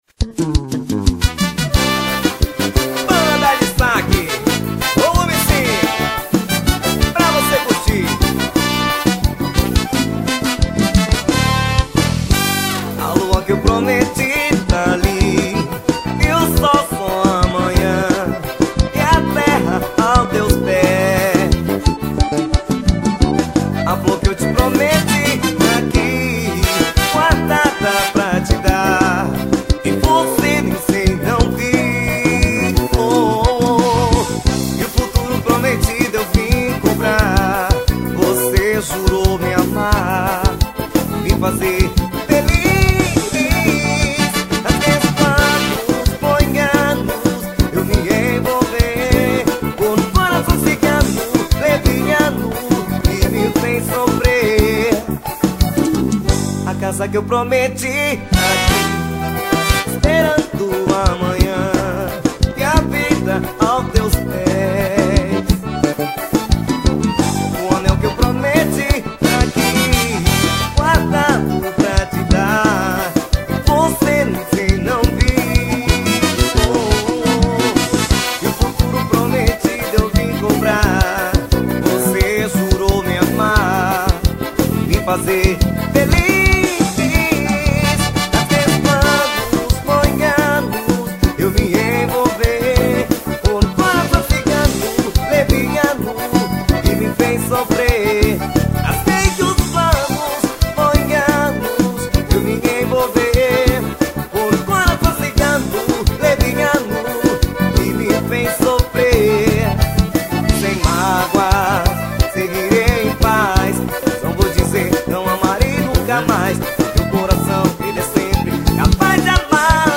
Composição: ao vivo.